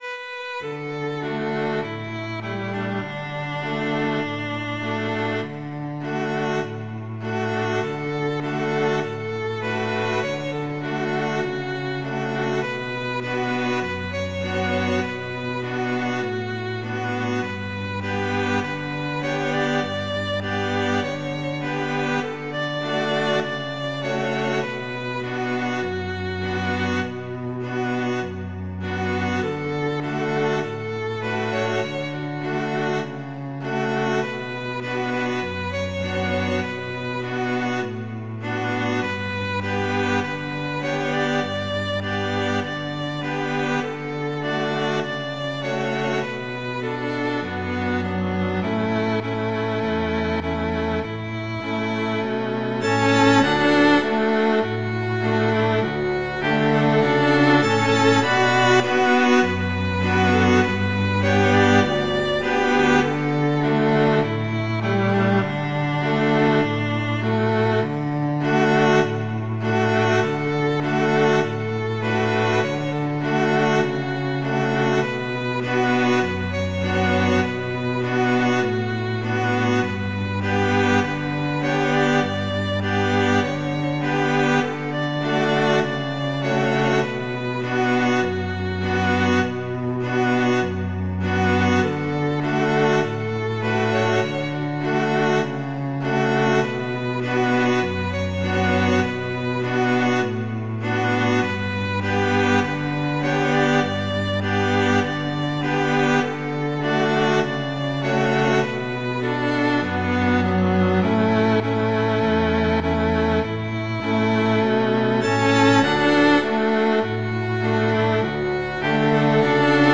traditional, irish